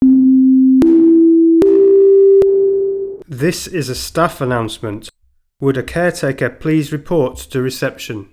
Commercial Audio are able to design and supply audio system components which can replace or work alongside your existing communication systems to provide site wide initiation of a lockdown in response to a threat as well as voice announcements to confirm the status of the emergency to all occupants.
Staff Alert
STAFF ALERT.wav